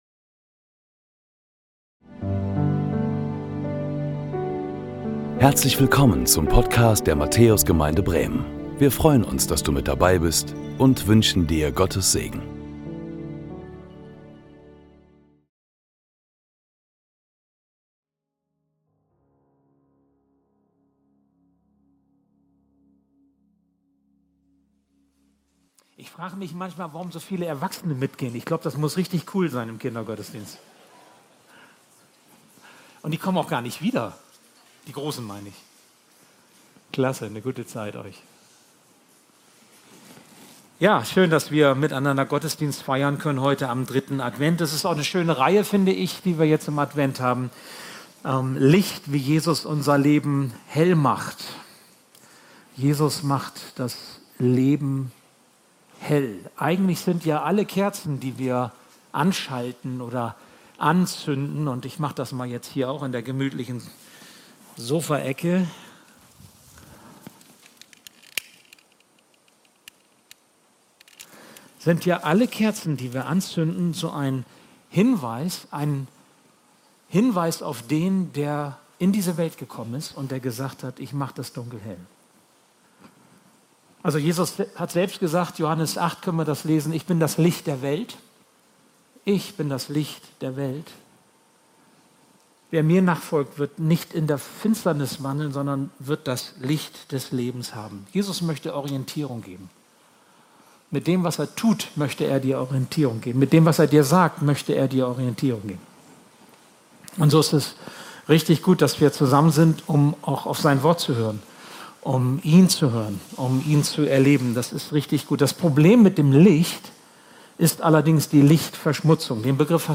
Predigten der Matthäus Gemeinde Bremen LICHT #3 Annahme Play Episode Pause Episode Mute/Unmute Episode Rewind 10 Seconds 1x Fast Forward 30 seconds 00:00 / 00:33:32 Abonnieren Teilen Apple Podcasts RSS Spotify RSS Feed Teilen Link Embed